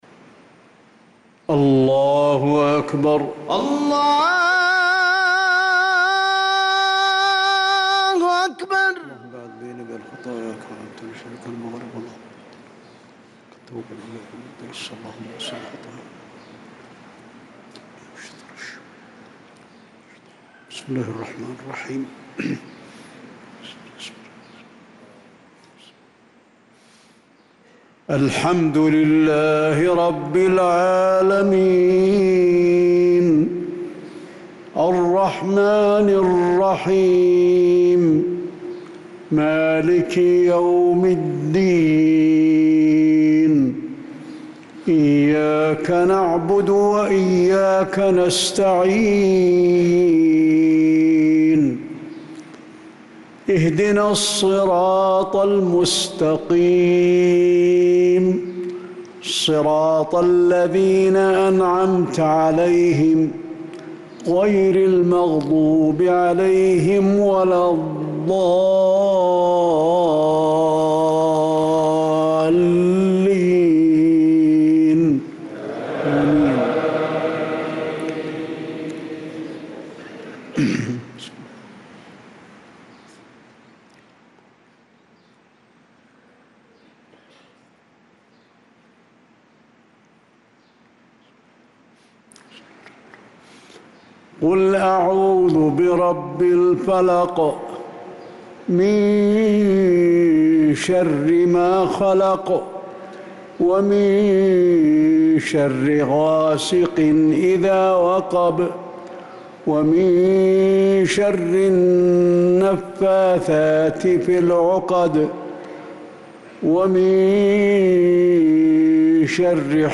صلاة المغرب للقارئ علي الحذيفي 12 ربيع الآخر 1446 هـ
تِلَاوَات الْحَرَمَيْن .